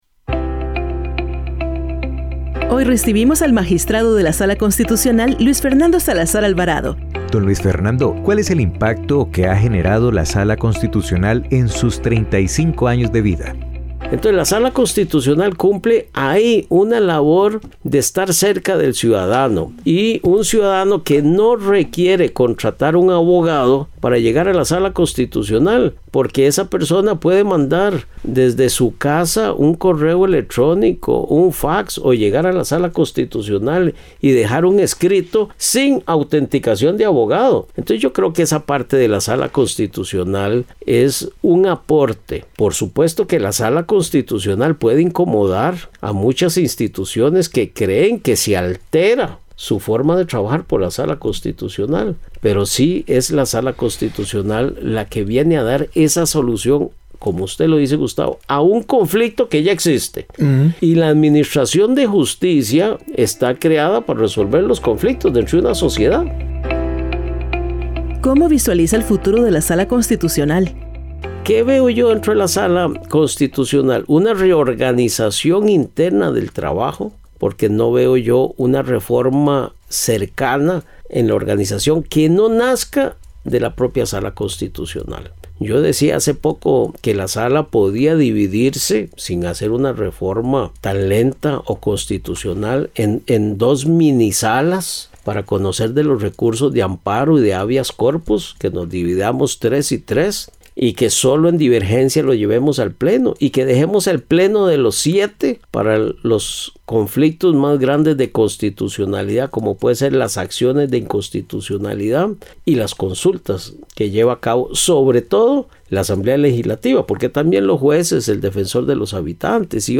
Entrevista al magistrado de la Sala Constitucional, Luis Fernando Salazar Alvarado